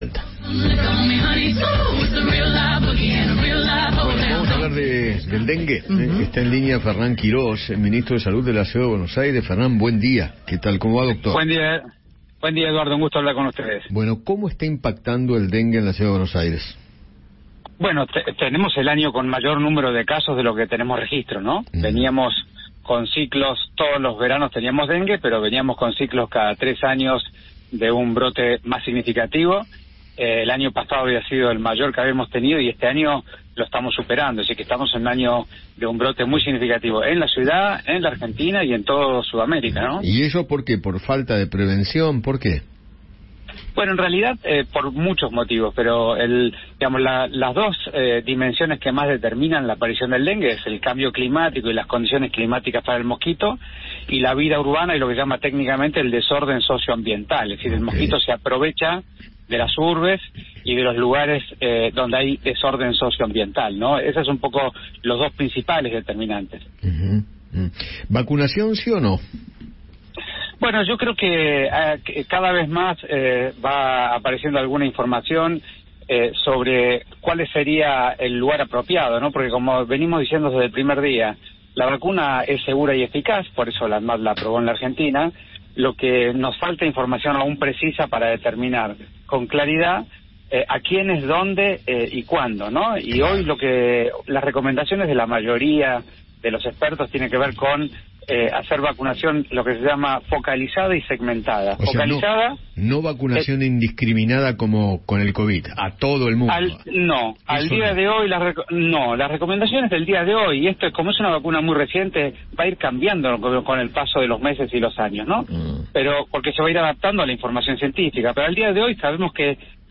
Fernán Quirós, ministro de Salud porteño, conversó con Eduardo Feinmann sobre el brote de dengue y las medidas de prevención que se aplican desde el Gobierno.